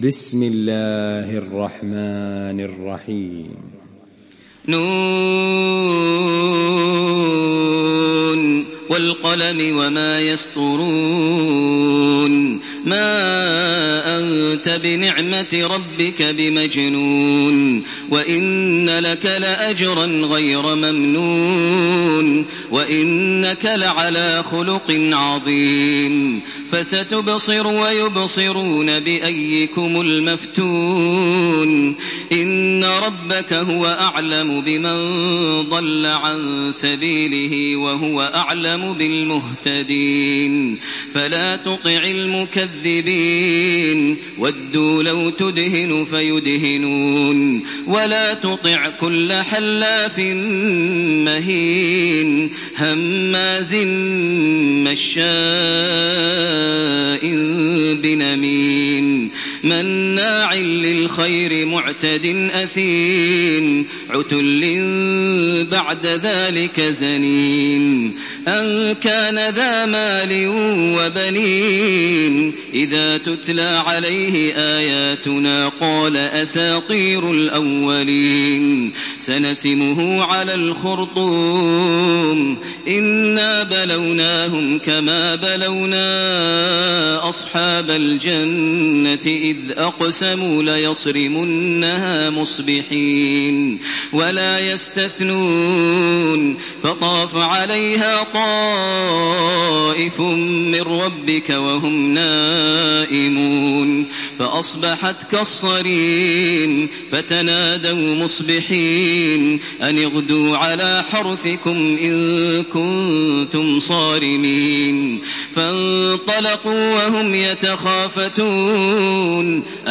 تراويح الحرم المكي 1428
مرتل